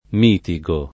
mitigo [ m & ti g o ], ‑ghi